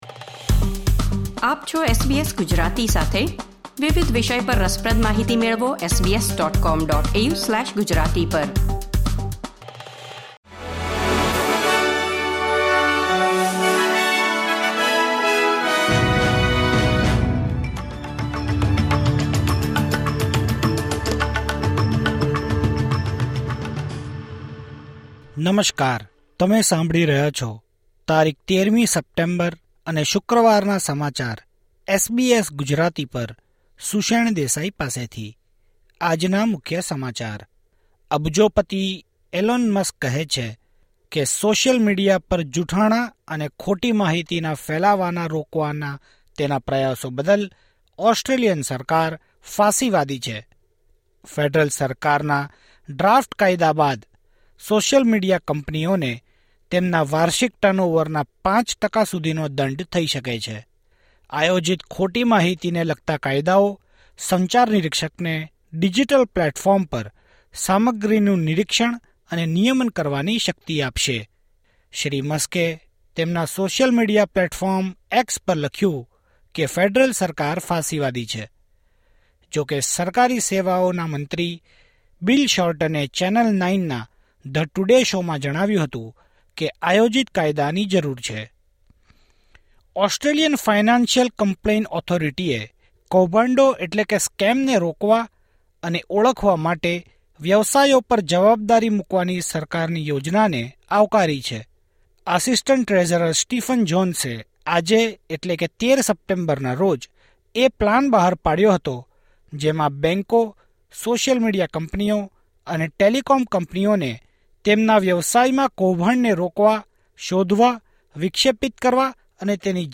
SBS Gujarati News Bulletin 13 September 2024